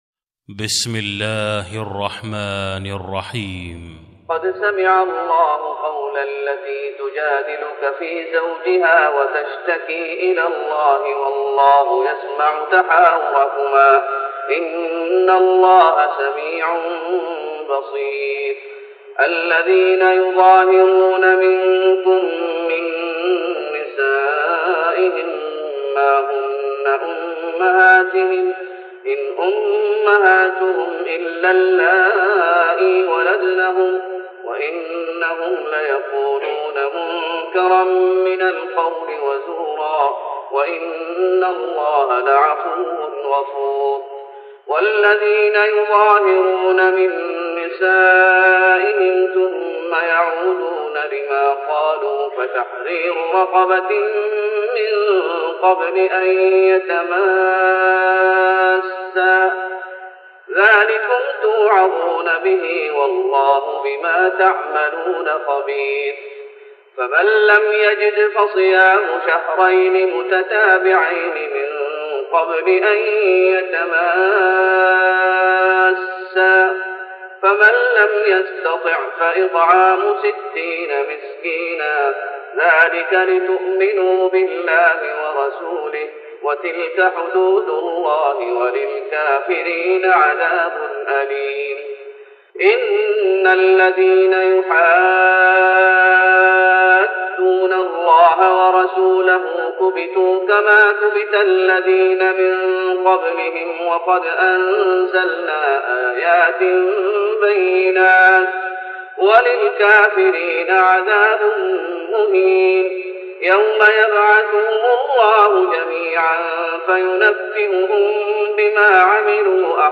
تراويح رمضان 1414هـ من سورة المجادلة Taraweeh Ramadan 1414H from Surah Al-Mujaadila > تراويح الشيخ محمد أيوب بالنبوي 1414 🕌 > التراويح - تلاوات الحرمين